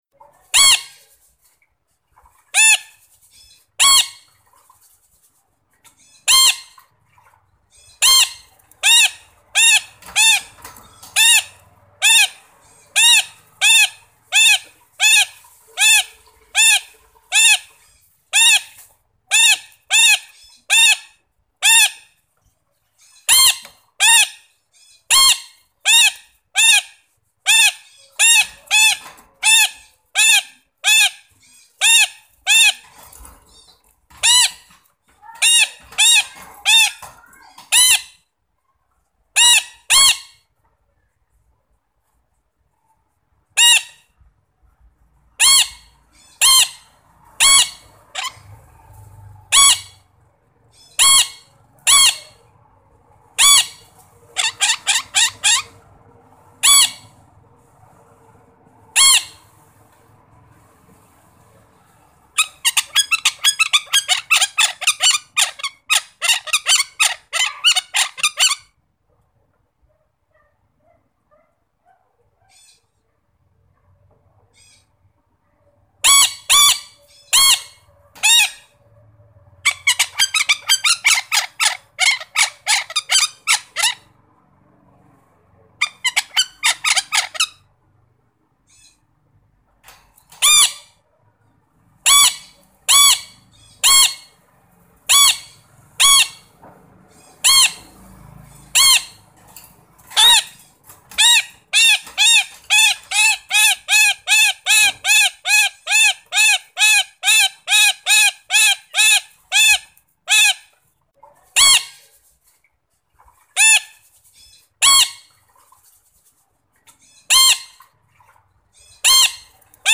Tiếng Két kêu
Thể loại: Tiếng chim
Description: Chim Két kêu với tiếng hót đặc trưng, thường được sử dụng trong các video về động vật. Tải tiếng Két kêu mp3 với âm thanh to, rõ ràng và không tạp âm, mang đến hiệu ứng âm thanh chuẩn nhất cho việc edit video.
tieng-ket-keu-www_tiengdong_com.mp3